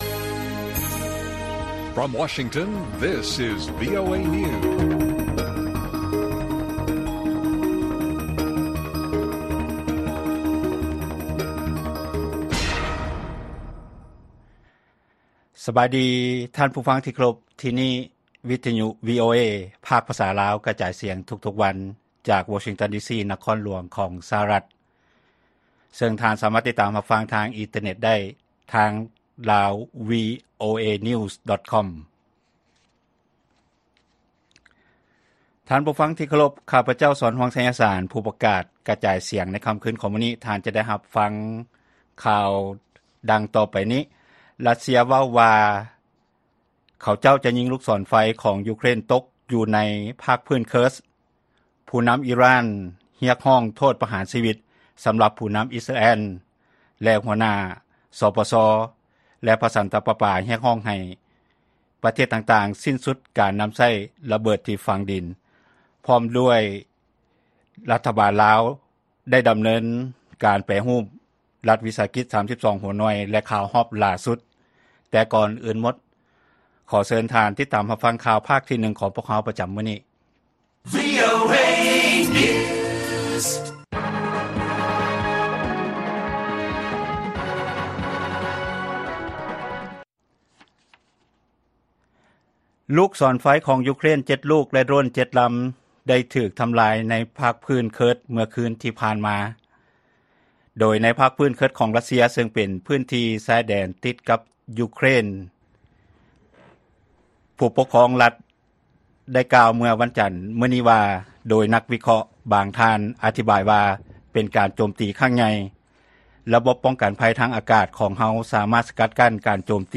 ລາຍການກະຈາຍສຽງຂອງວີໂອເອລາວ: ຣັດເຊຍ ເວົ້າວ່າເຂົາເຈົ້າຍິງລູກສອນໄຟຂອງ ຢູເຄຣນ ຕົກ 7 ລູກໃນພາກພື້ນເຄີສຄ໌